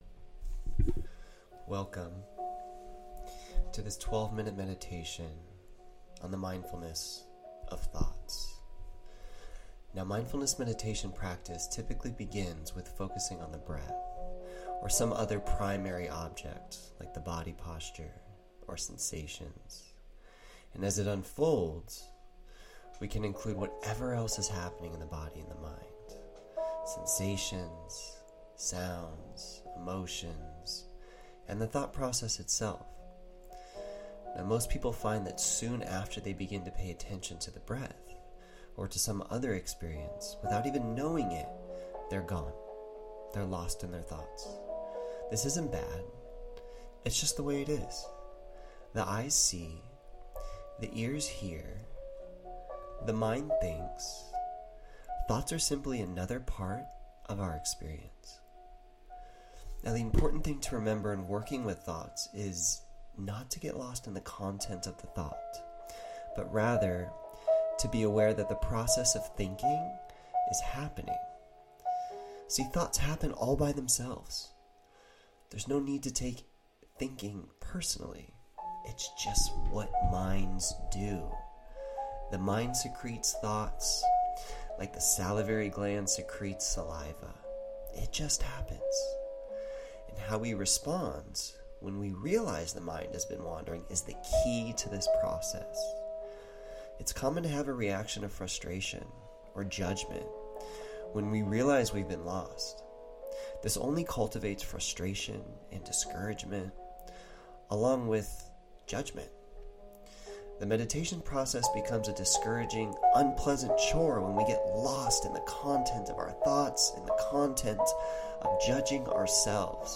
Mindfulness of Thoughts Meditation
Alchemy-SD-Mindfulness-of-Thoughts-with-MUSIC_Meditation_AUDIO.mp3